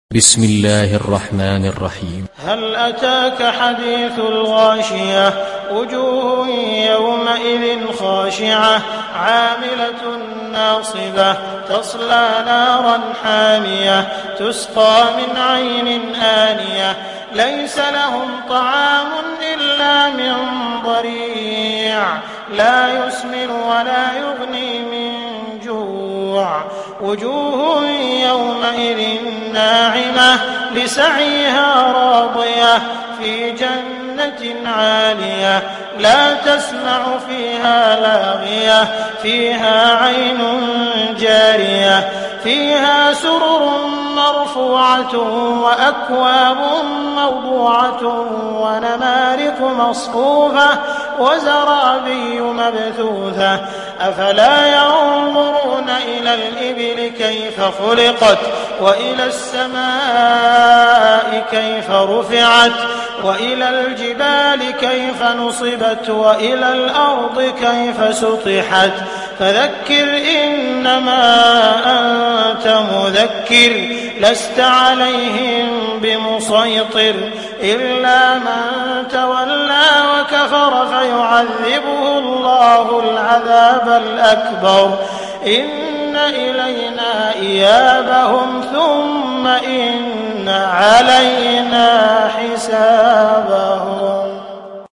تحميل سورة الغاشية mp3 بصوت عبد الرحمن السديس برواية حفص عن عاصم, تحميل استماع القرآن الكريم على الجوال mp3 كاملا بروابط مباشرة وسريعة